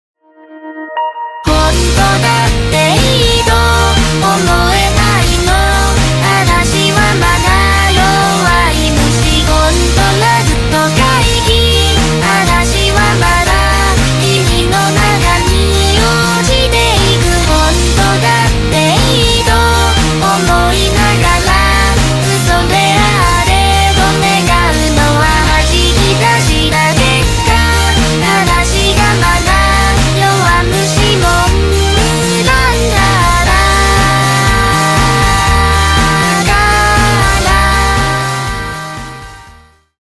Вокалоиды